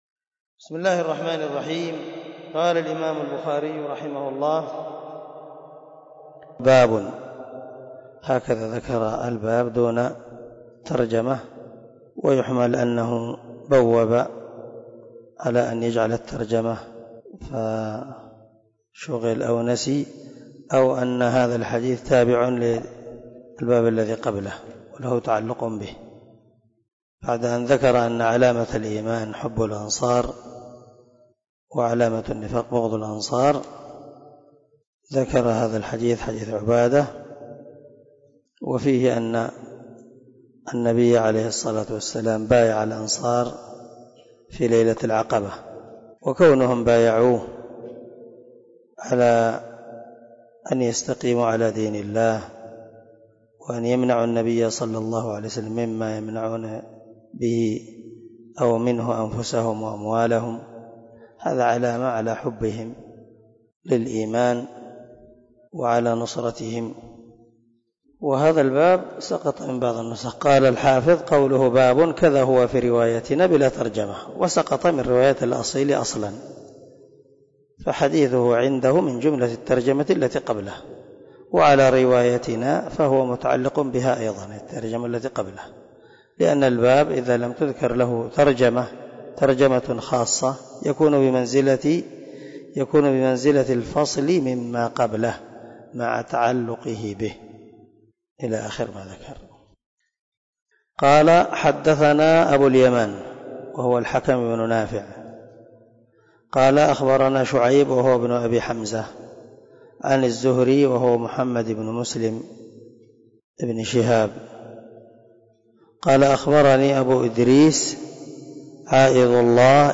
020الدرس 10 من شرح كتاب الإيمان حديث رقم ( 18 ) من صحيح البخاري
✒ دار الحديث- المَحاوِلة- الصبيحة.